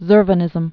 (zûrvə-nĭzəm)